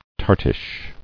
[tart·ish]